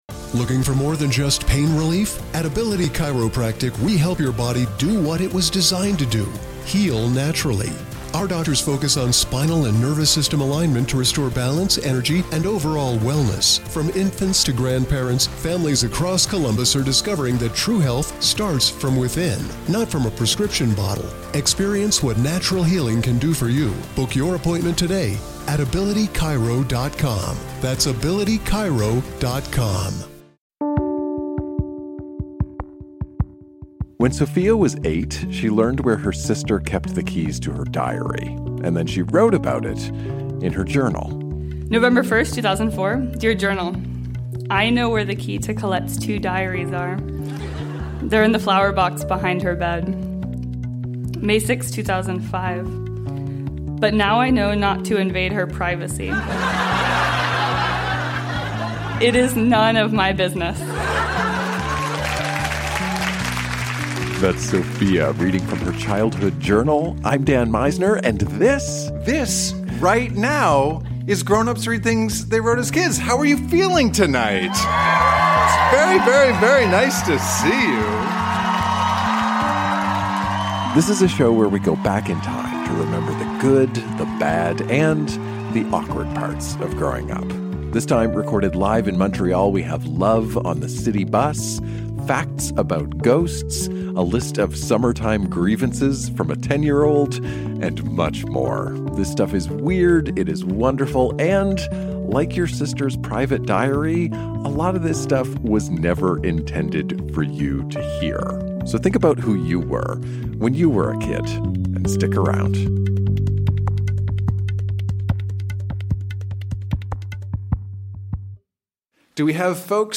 Recorded live at La Sala Rossa in Montréal.